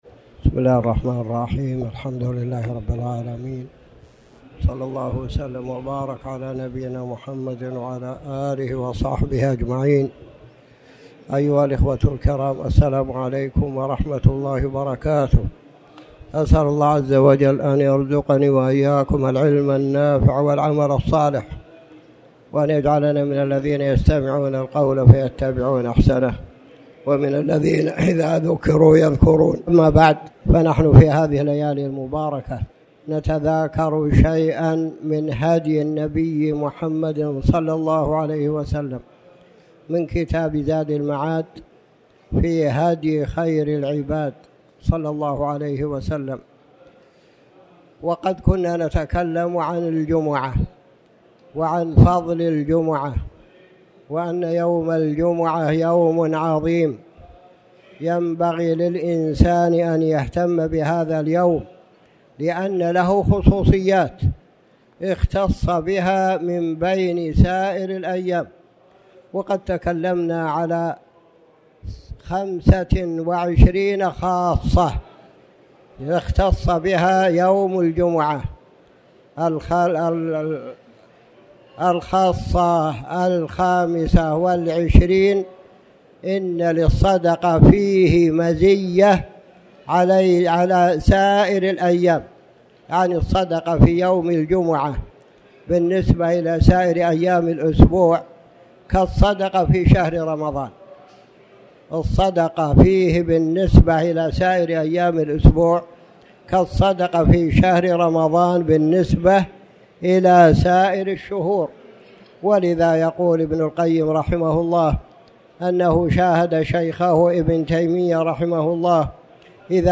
تاريخ النشر ٢٣ ذو الحجة ١٤٣٩ هـ المكان: المسجد الحرام الشيخ